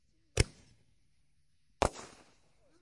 烟花 " 烟花28
描述：使用Tascam DR05板载麦克风和Tascam DR60的组合使用立体声领夹式麦克风和Sennheiser MD421录制烟花。
Tag: 高手 焰火 裂纹